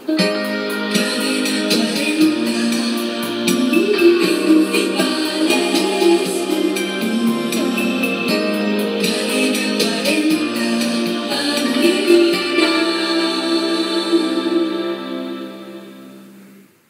Dos indicatius de la ràdio